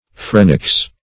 Search Result for " phrenics" : The Collaborative International Dictionary of English v.0.48: Phrenics \Phren"ics\ (fr[e^]n"[i^]ks), n. That branch of science which relates to the mind; mental philosophy.